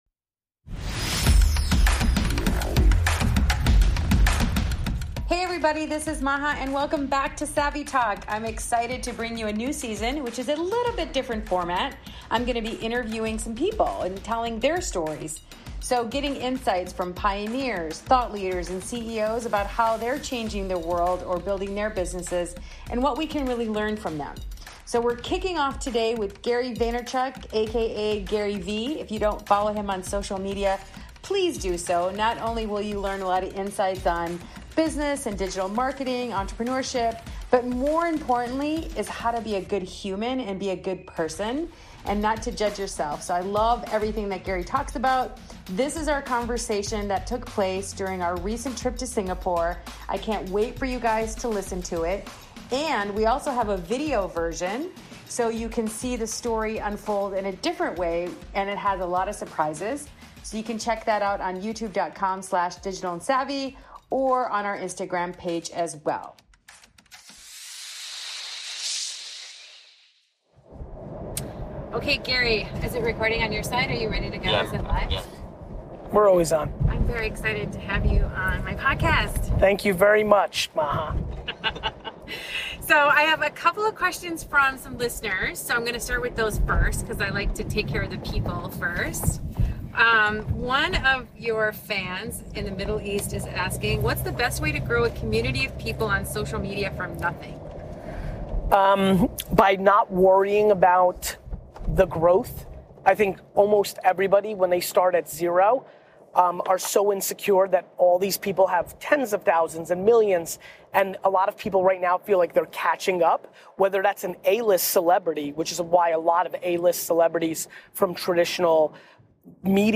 He’s a busy guy, so the best way to get my questions answered was to catch him as he drove between meetings.